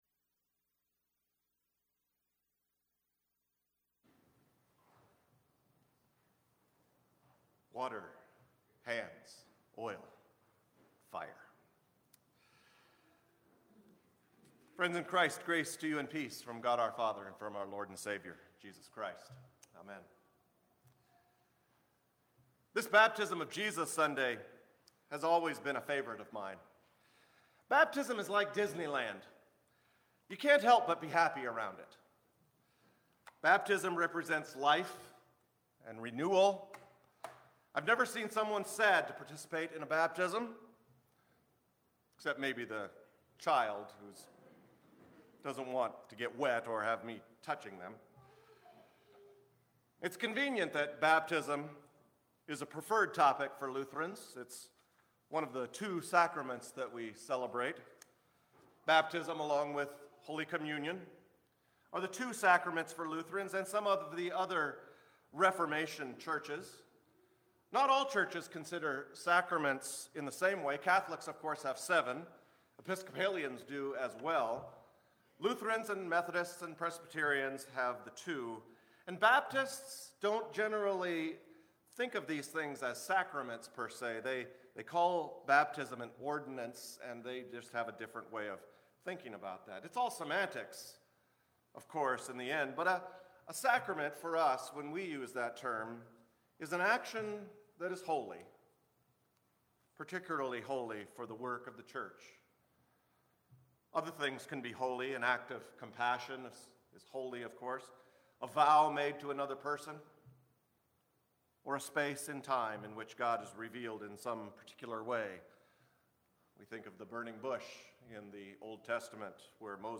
Sermons | Bethlehem Lutheran Church